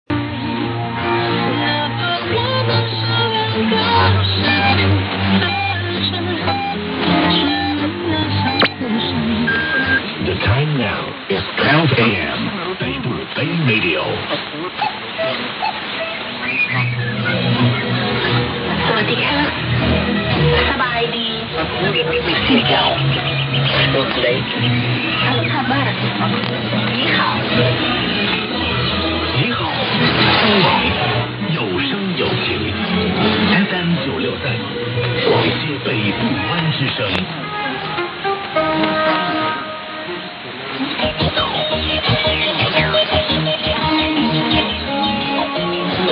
・このＨＰに載ってい音声(ＩＳとＩＤ等)は、当家(POST No. 488-xxxx)愛知県尾張旭市で受信した物です。
ID: identification announcement